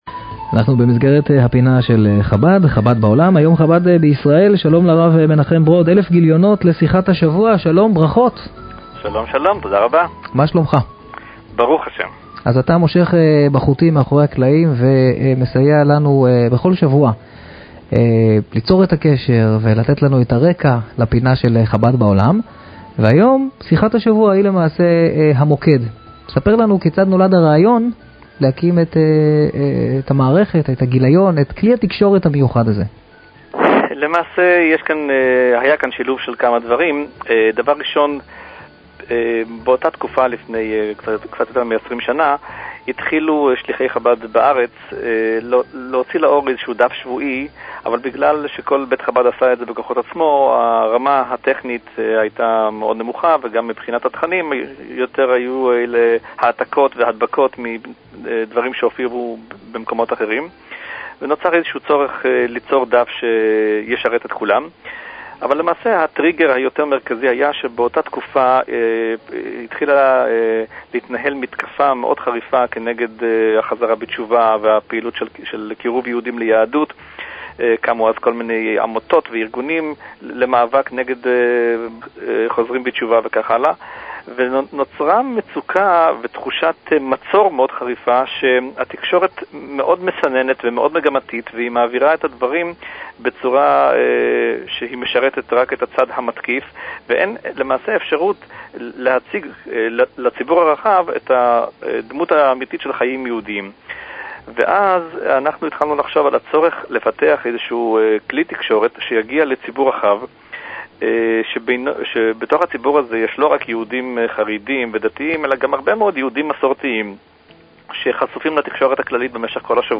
היה אורח הפינה הקבועה של ראיון עם שליח חב"ד